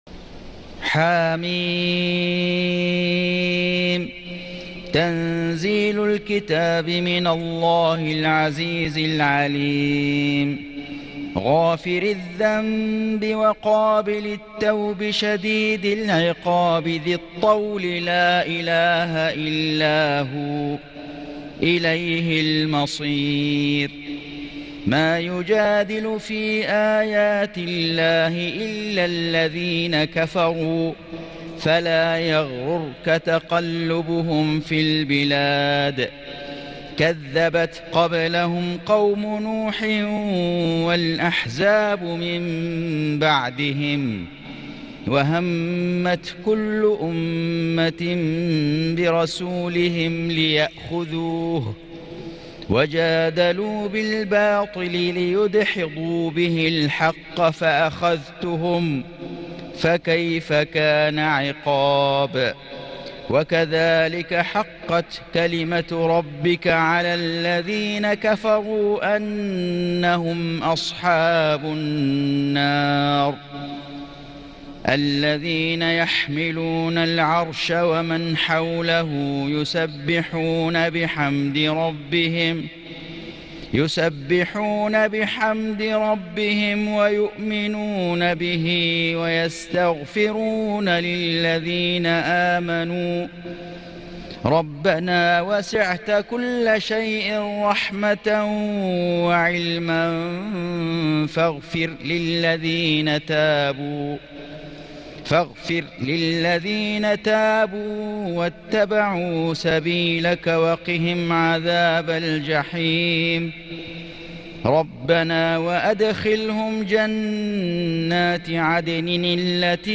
سورة غافر > السور المكتملة للشيخ فيصل غزاوي من الحرم المكي 🕋 > السور المكتملة 🕋 > المزيد - تلاوات الحرمين